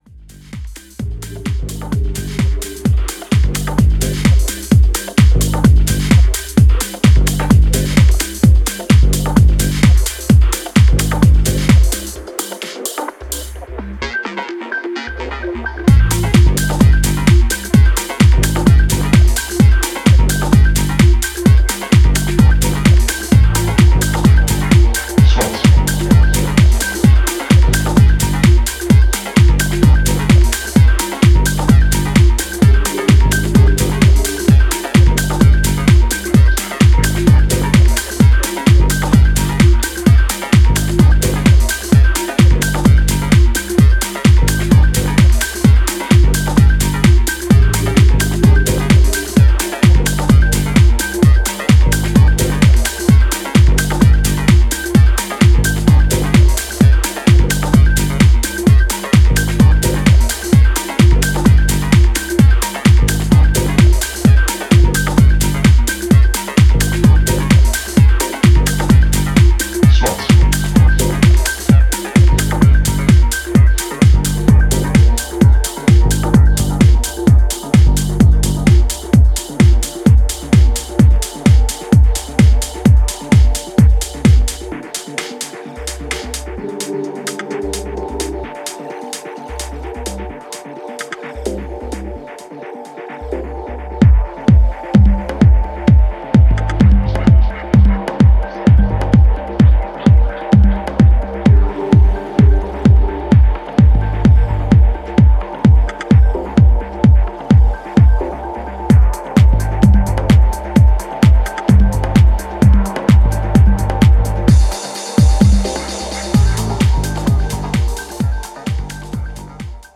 圧の強いベースラインを基軸にユーフォリックで音数の多いウワモノを配した
ダークでソリッドなミニマル/モダン・テック・ハウス路線の楽曲を展開しています。